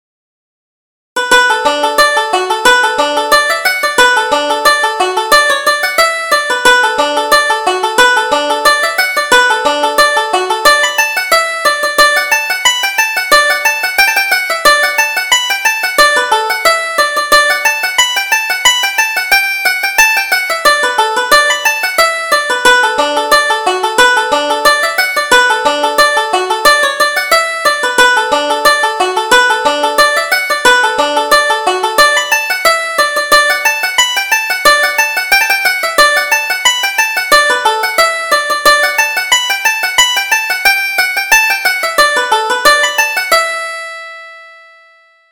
Reel: Jenny Pippin